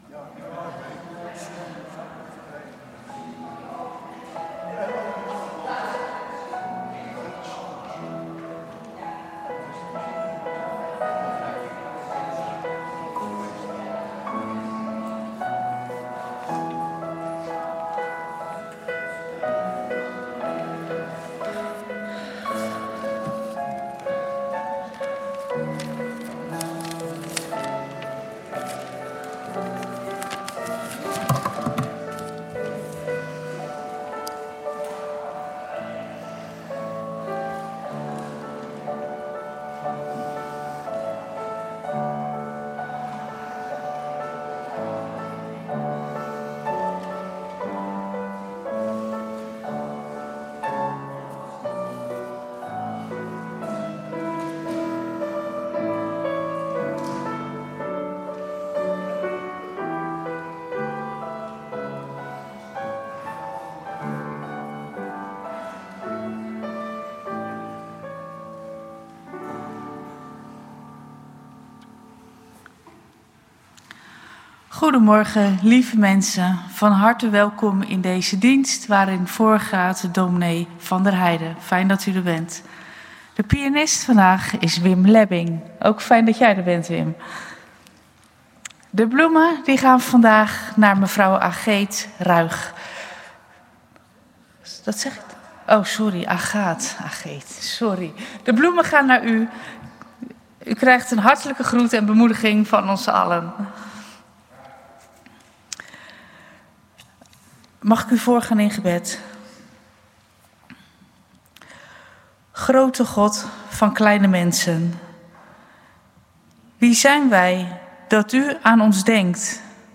U vindt ons in de Grote Kerk van Oostzaan voor kerkdiensten en onze activiteiten.
Kerkdienst gemist?